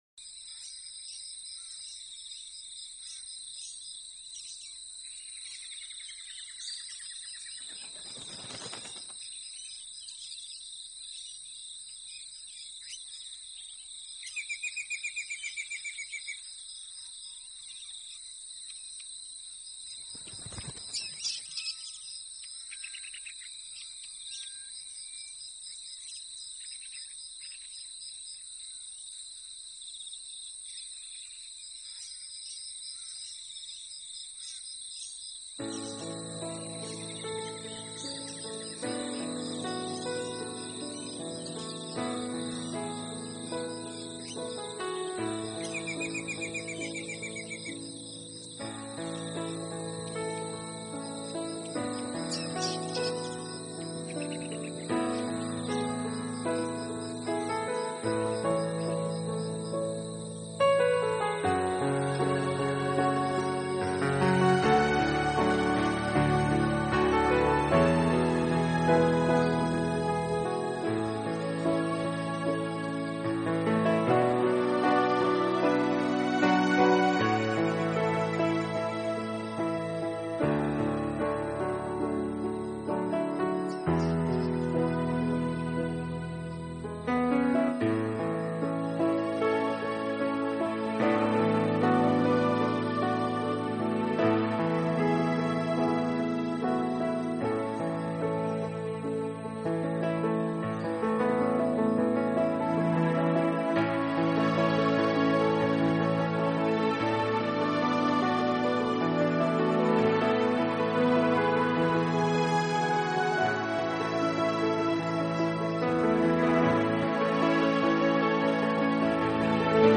Genre..........: New Age
QUALiTY........: MP3 44,1kHz / Stereo